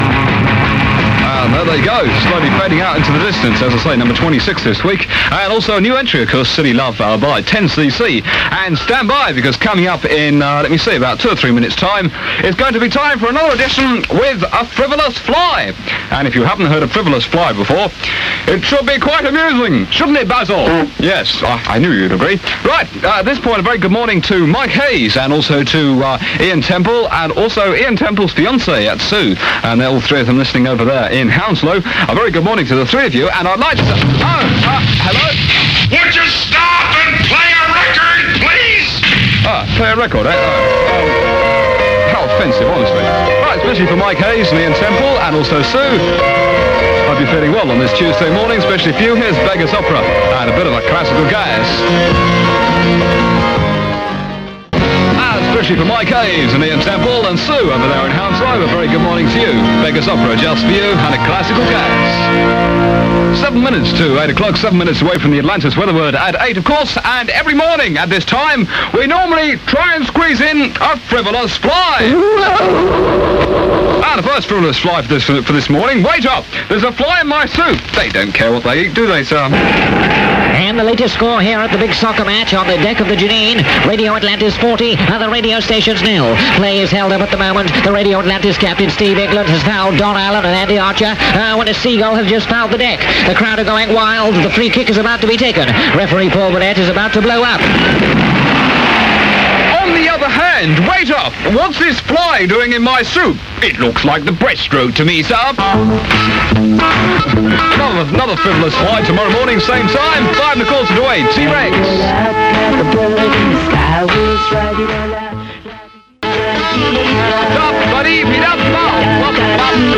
Many of these tapes are studio recordings and hopefully better quality than you will have heard before.
Radio Atlantis was heavily influenced by the offshore stations of the previous decade, with plenty of jingles and an upbeat fun presentation.